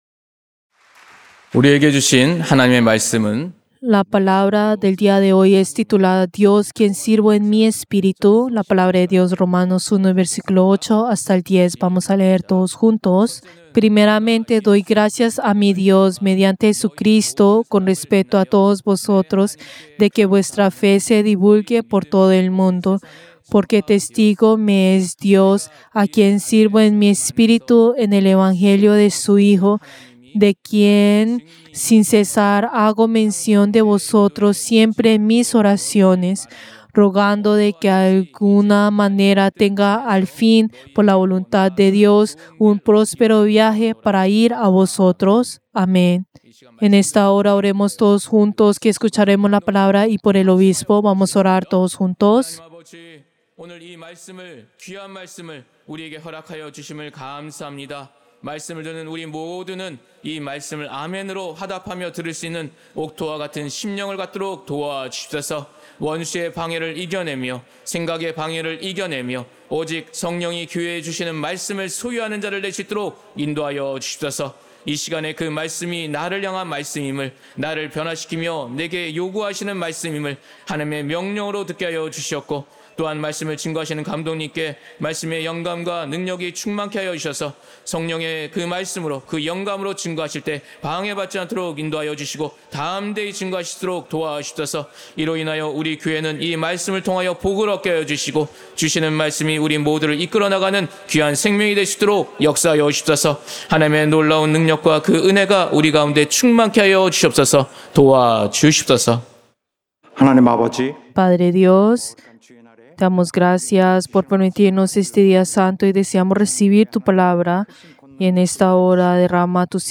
Servicio del Día del Señor del 19 de octubre del 2025